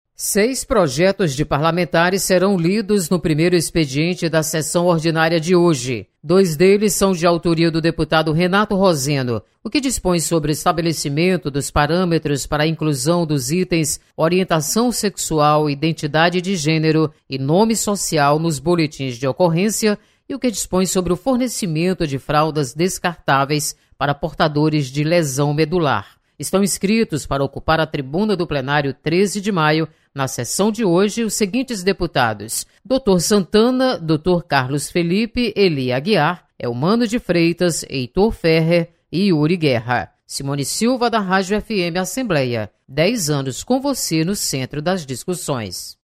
Acompanhe as informações do expediente legislativo desta terça-feira (31/10). Repórter